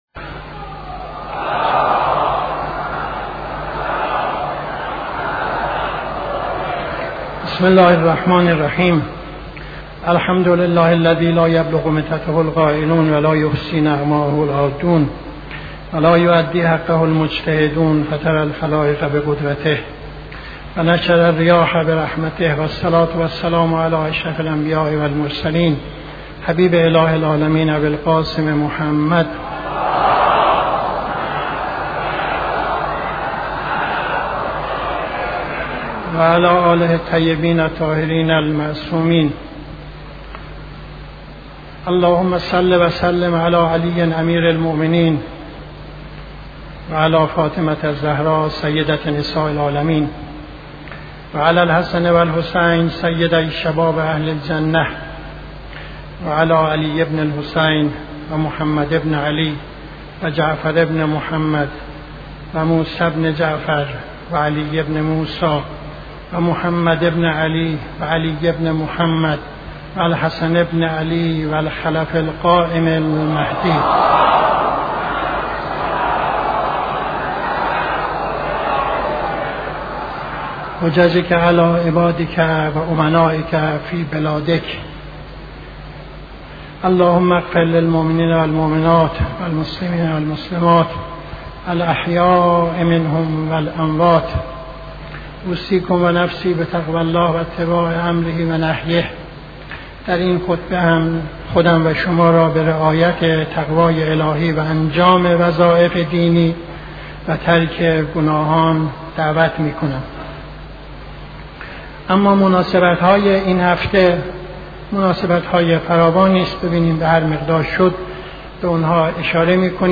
خطبه دوم نماز جمعه 10-06-74